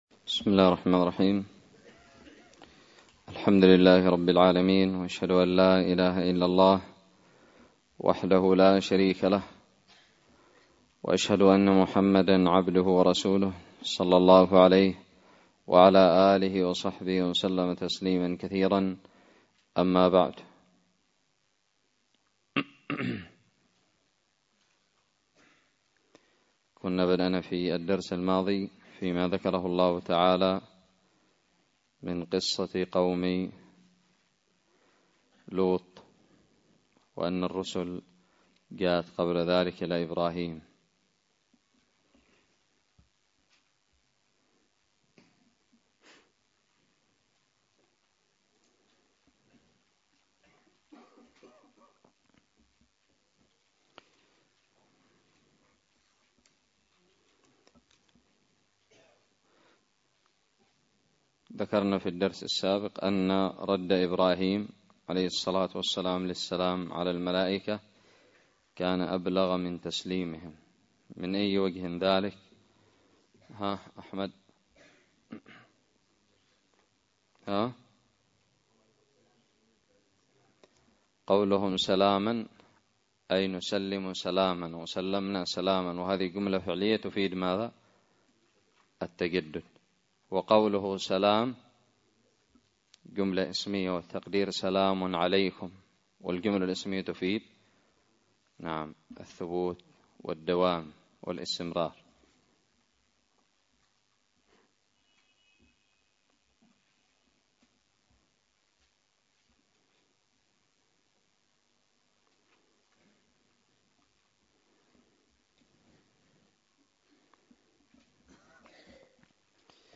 الدرس الحادي والعشرون من تفسير سورة هود
ألقيت بدار الحديث السلفية للعلوم الشرعية بالضالع